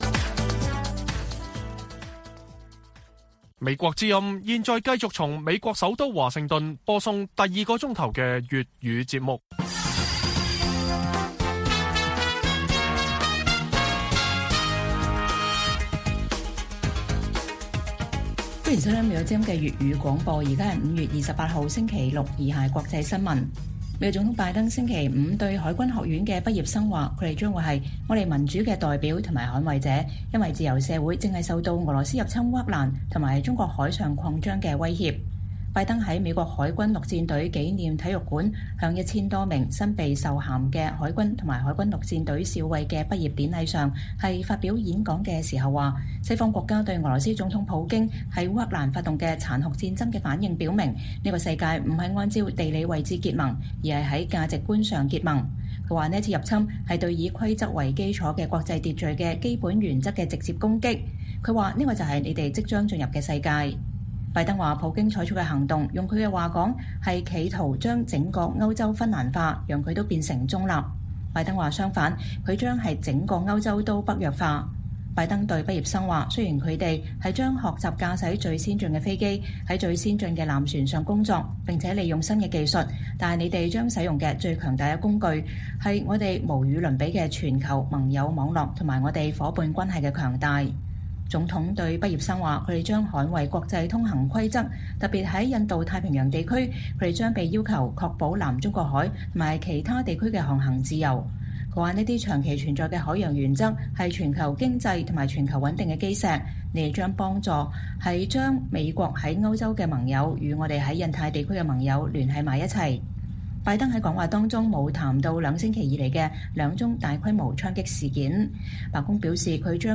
粵語新聞 晚上10-11點：拜登告訴海軍學院畢業生: 普京將歐洲“北約化”